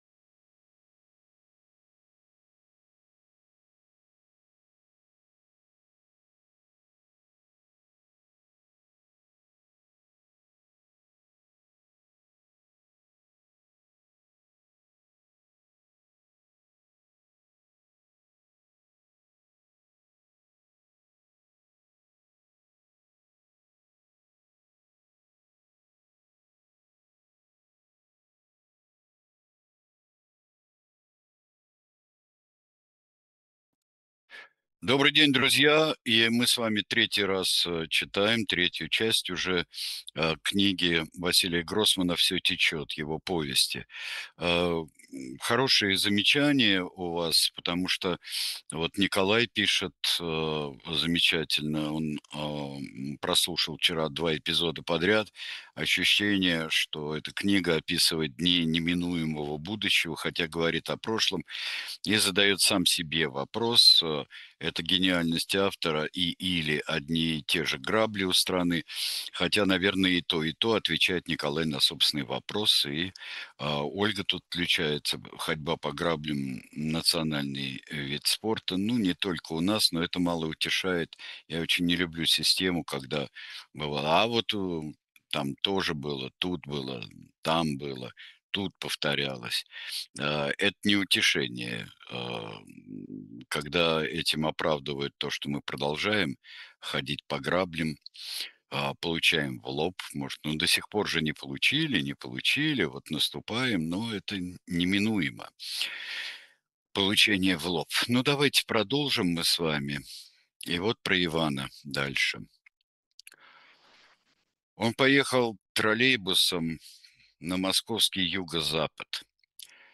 Читает Сергей Бунтман